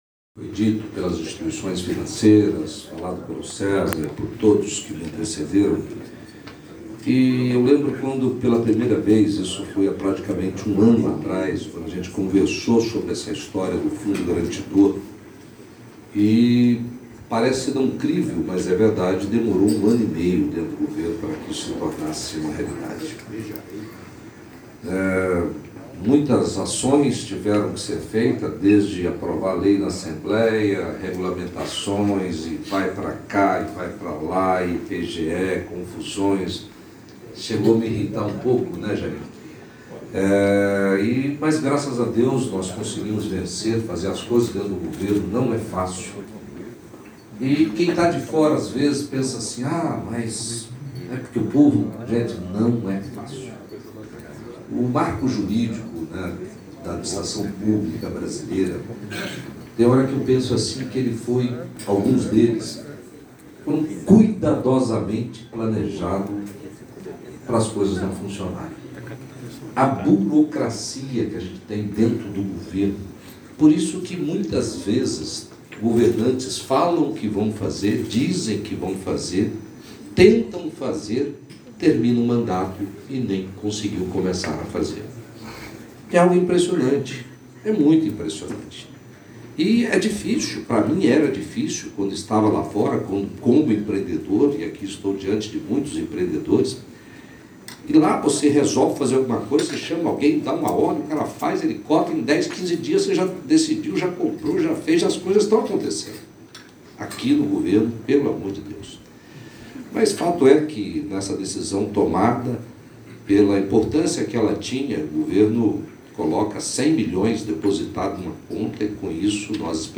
Em entrevista à imprensa o governador de MT Mauro Mendes (UB), disse que fica feliz com esse programa que deve apoiar o pequeno e médio empreendedor.